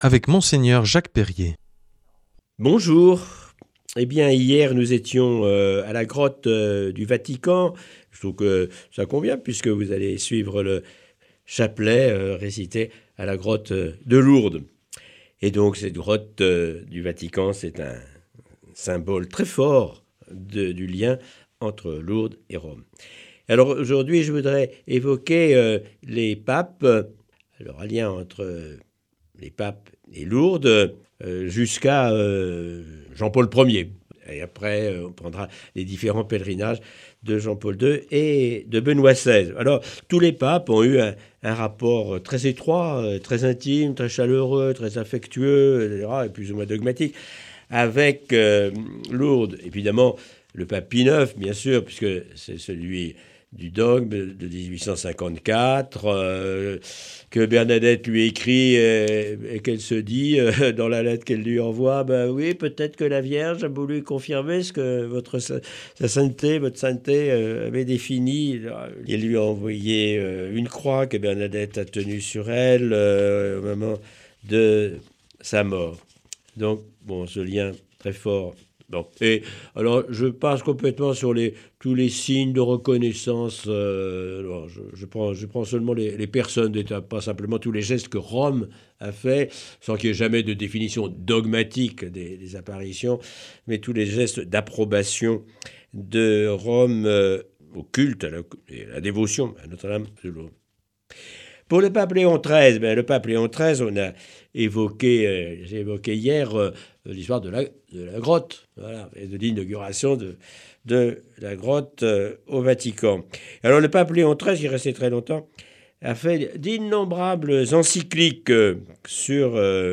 Enseignement Marial du 01 juil.